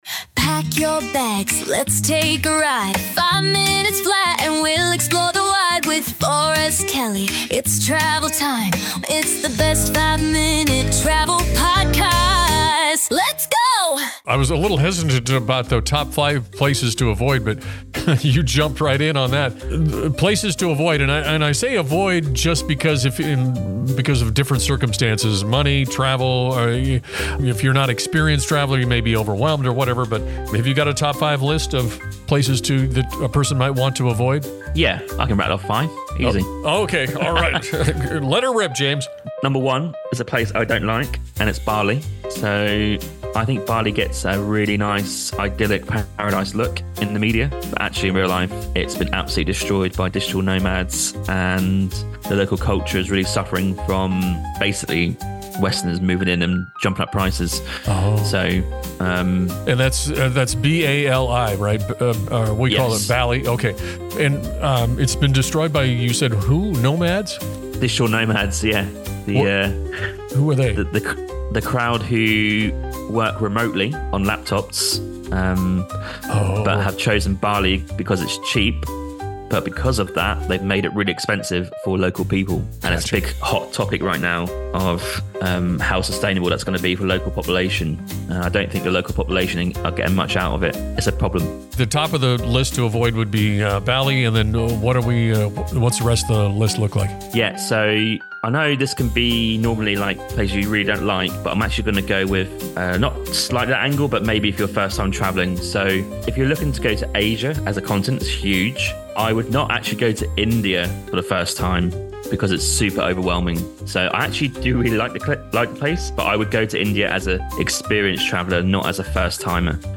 Tune in for a light-hearted yet informative discussion that blends travel wisdom with a hefty dose of humor!